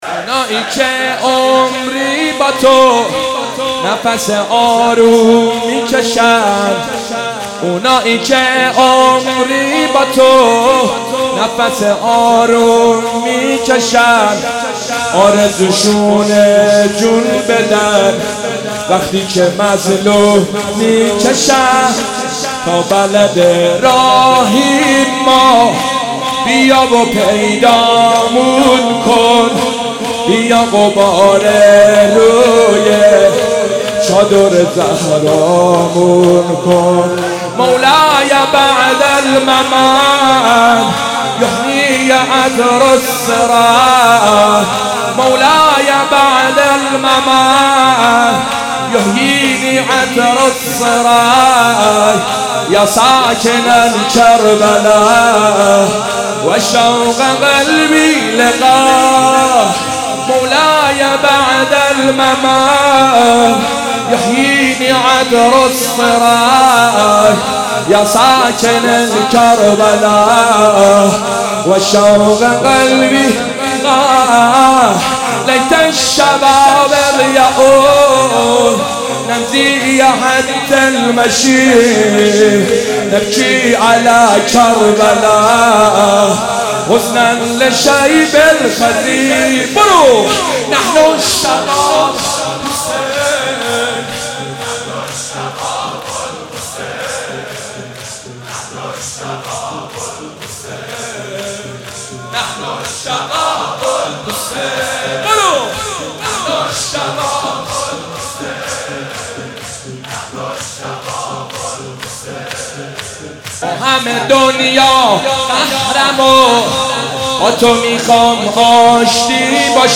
در شب هفتم محرم